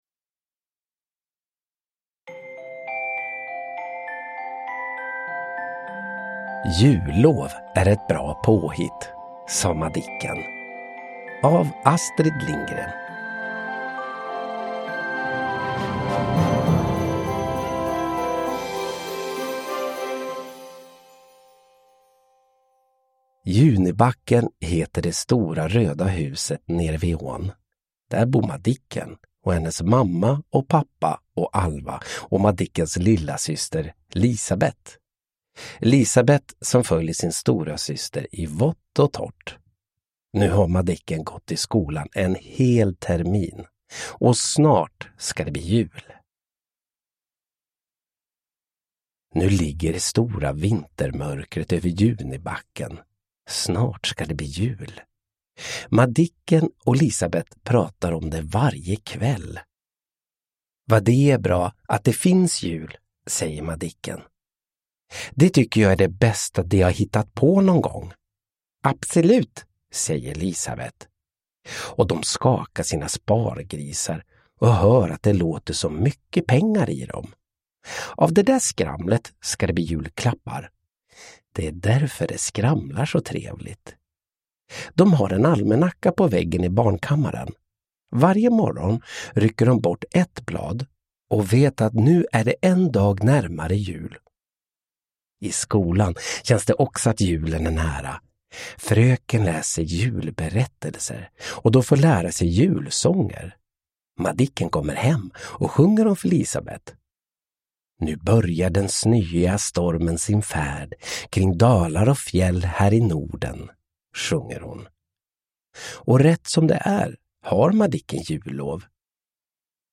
Jullov är ett bra påhitt, sa Madicken – Ljudbok
Uppläsare: Olof Wretling